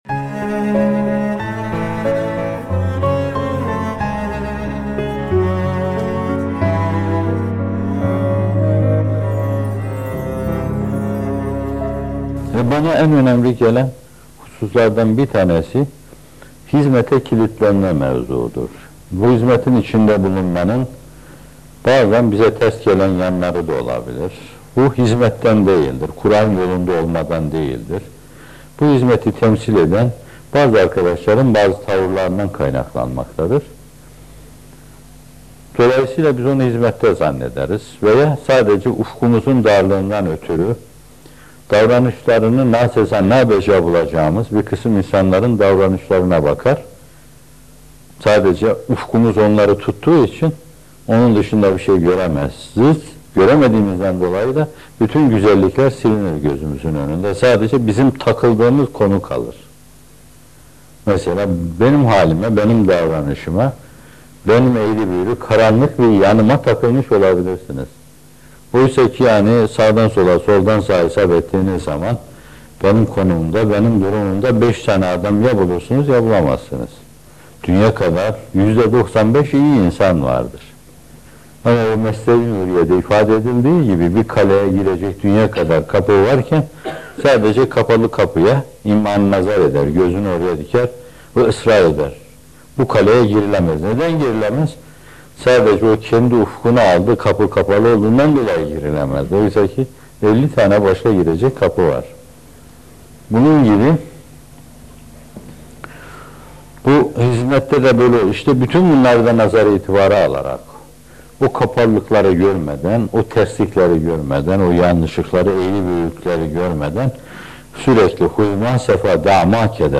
Teslimiyet ve Hizmet Ufku - Fethullah Gülen Hocaefendi'nin Sohbetleri